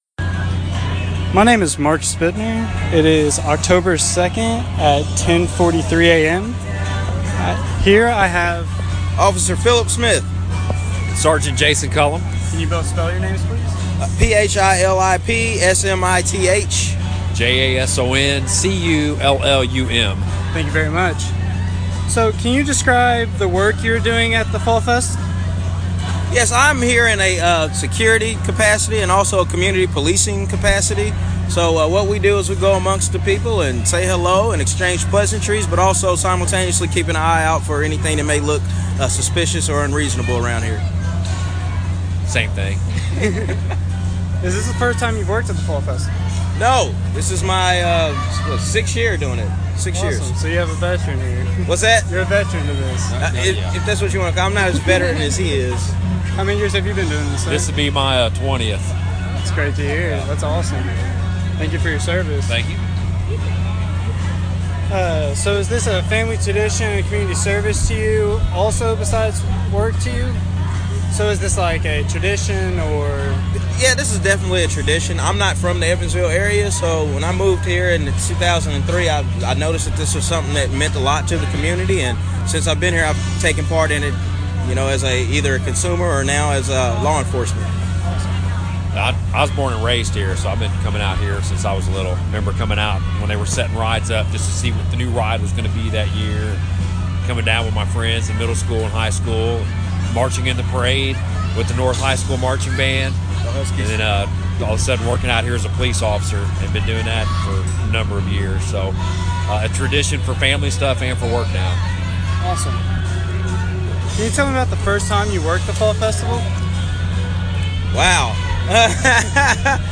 University Archives & Special Collections > Oral History Collection